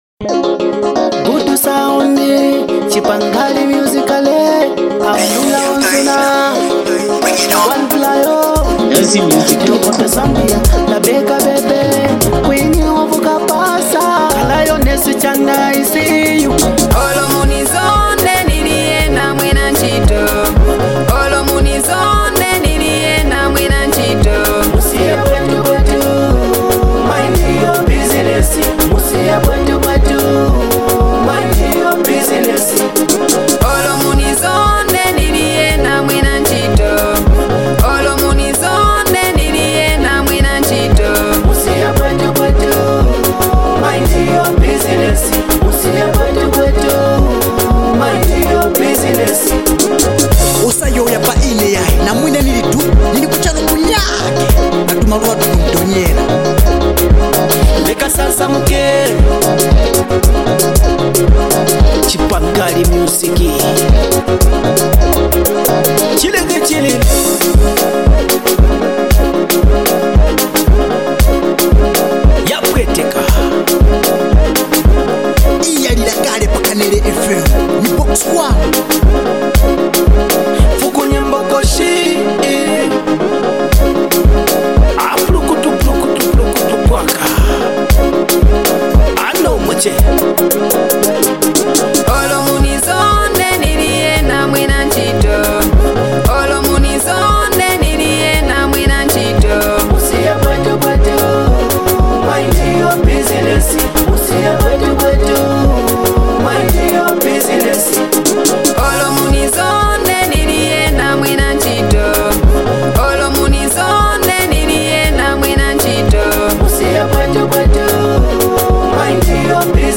a female artist